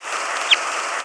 Red Crossbill flight calls
Type 2 call form bird in flight.